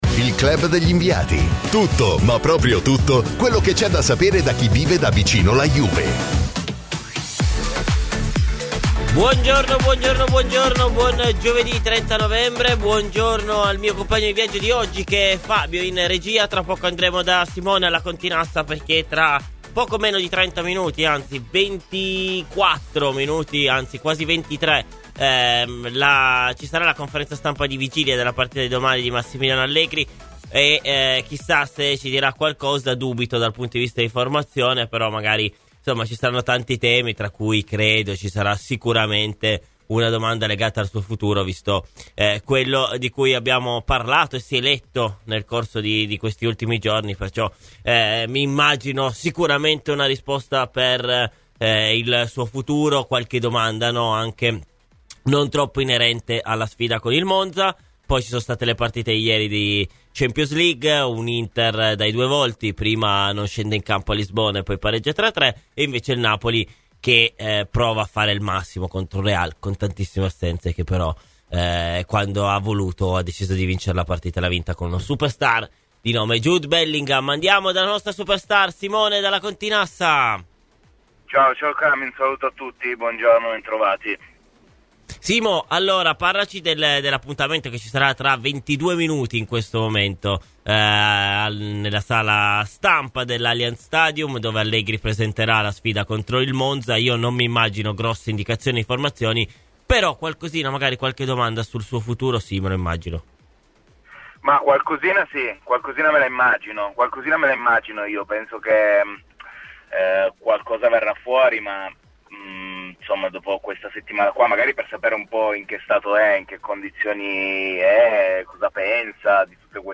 Ospite nel corso del “Club degli Inviati” su Radio Bianconera